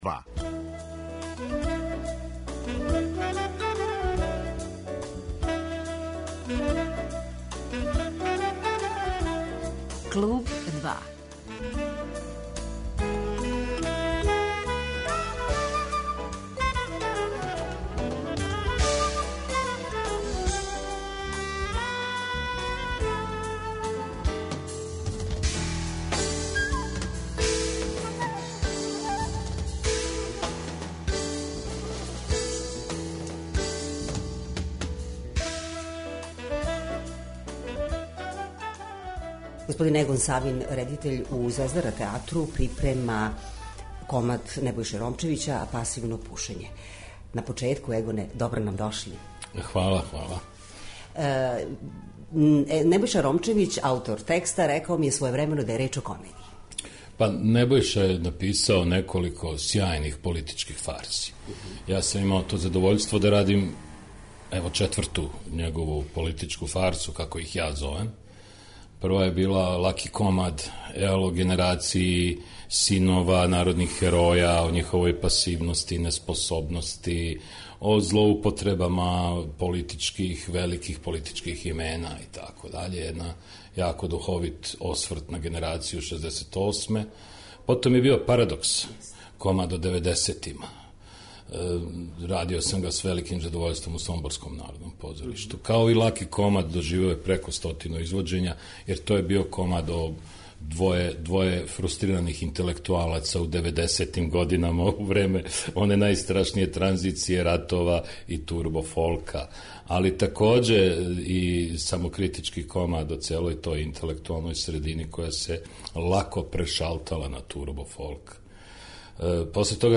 Cвоја размишљања о позоришту, са слушаоцима ће поделити редитељ Егон Савин.